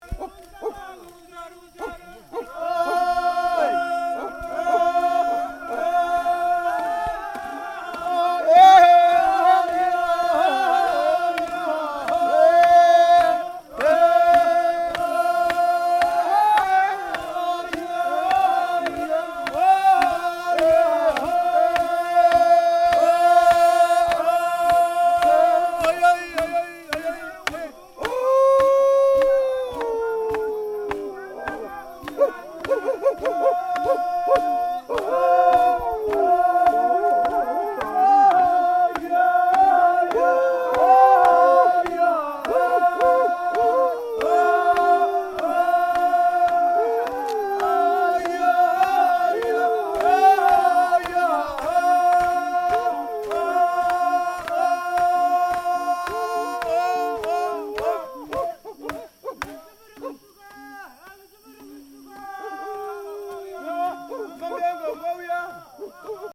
Tokimba Ceremony-calling in the spirits
tokimba-ceremony-1.mp3